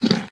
sounds / entity / horse / eat5.ogg
eat5.ogg